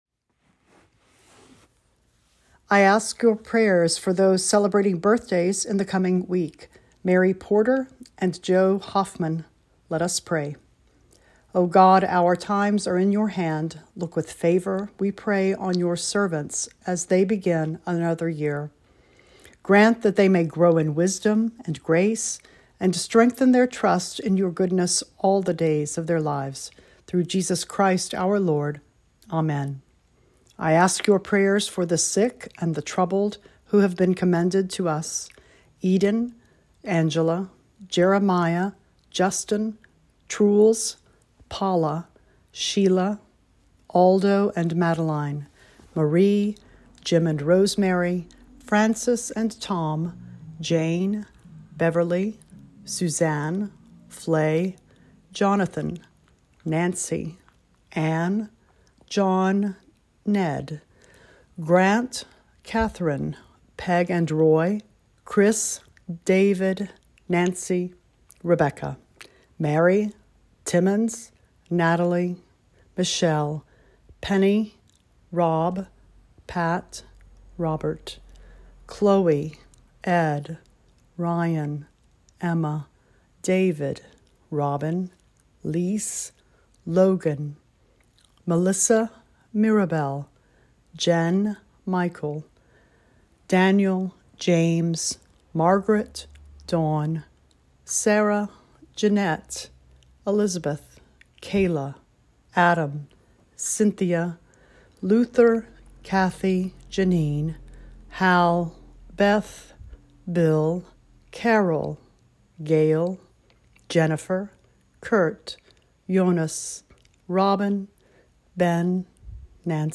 Weekly Prayer Recording: